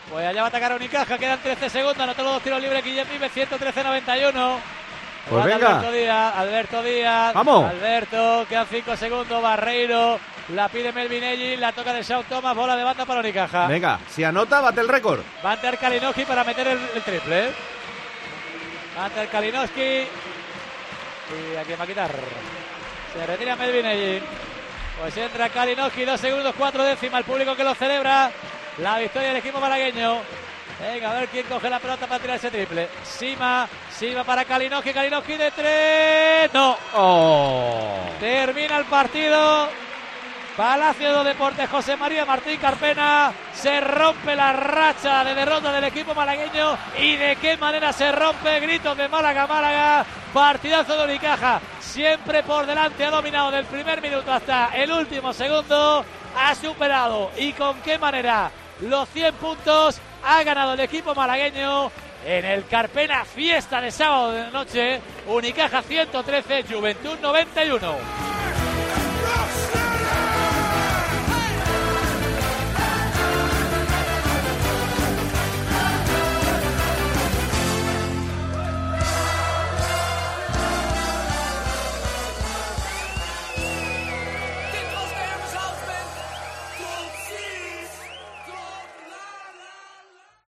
Así te hemos narrado en COPE MÁS Málaga el final del Unicaja-Joventut (113-91)